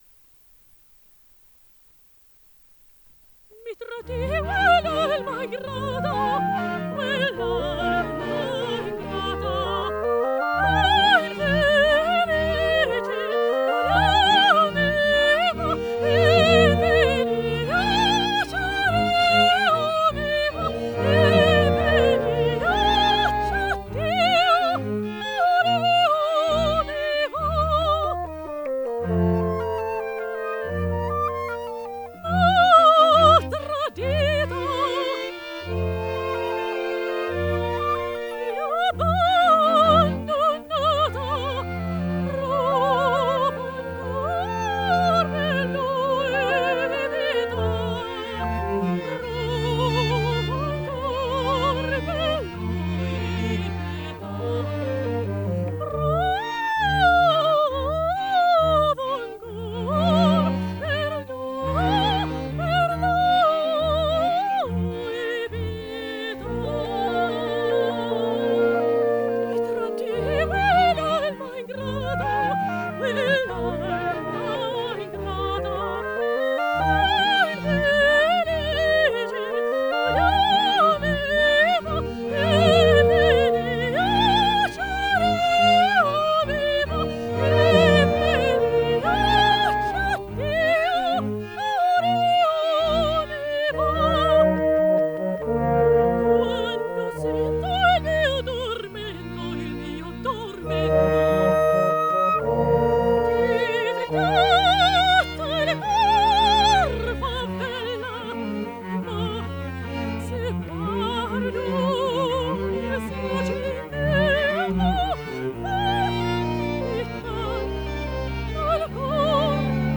MOZART_ANECHOIC.wav